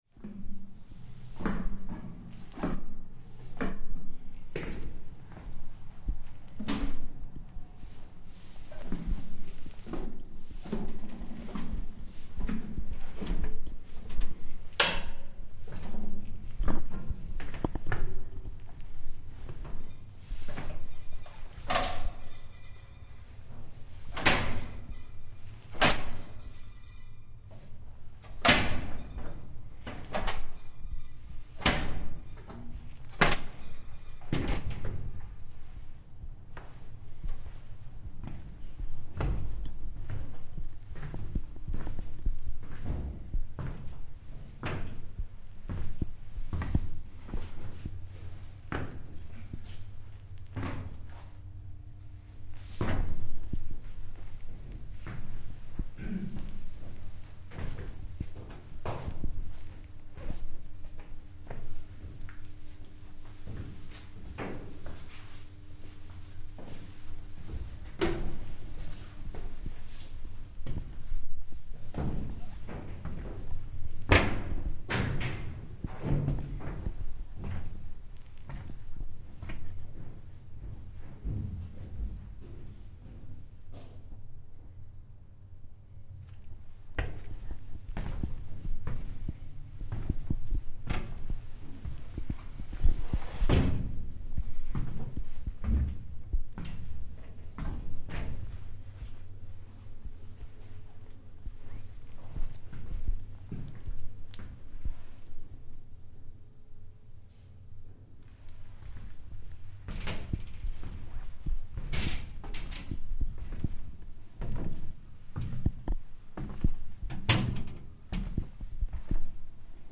In the engine room
Aboard the Suur Toll museum ship in Tallinn, we take a tour around the engine deck, exploring the fore and aft engine rooms, before climbing a metal ladder and listening to a recreation of the sound of the ship's engines at work, and the regular ship's bell.